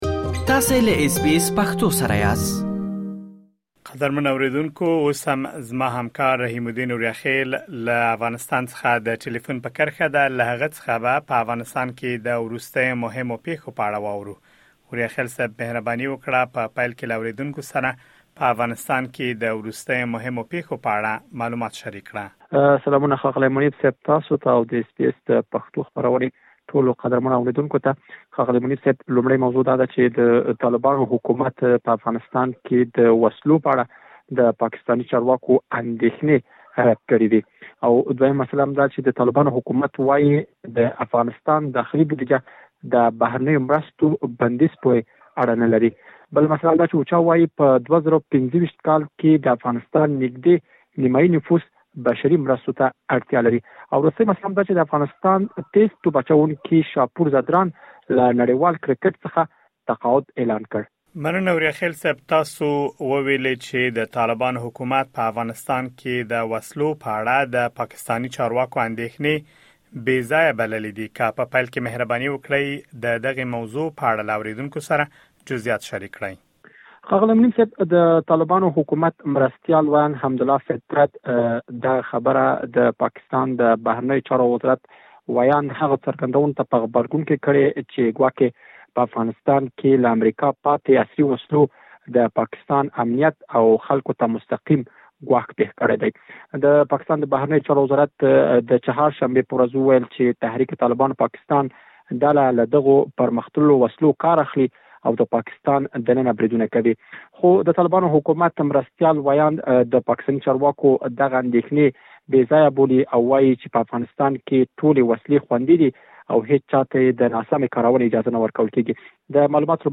د افغانستان د وروستیو پېښو په اړه مهم معلومات په ترسره شوې مرکې کې اورېدلی شئ.